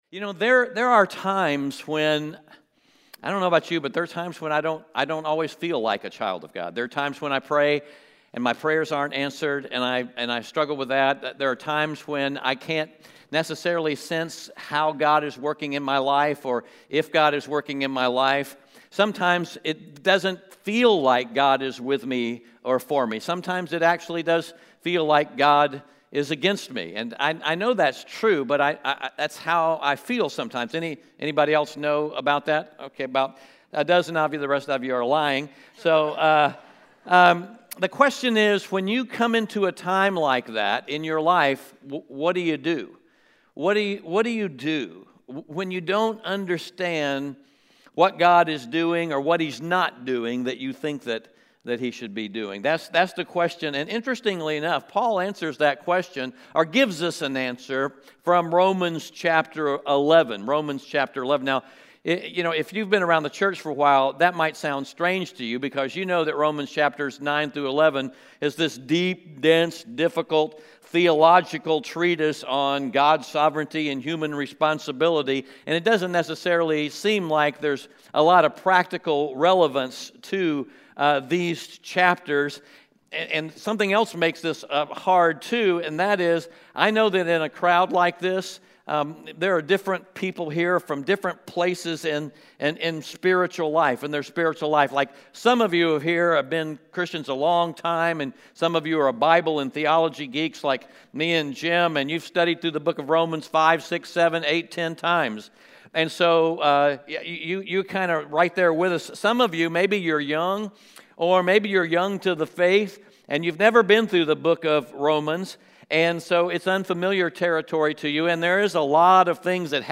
Romans 11:1-36 Audio Sermon Notes (PDF) Onscreen Notes Ask a Question To understand what Paul is trying to tell us in Romans 11, we need to be able to follow his flow of thought through the entire chapter.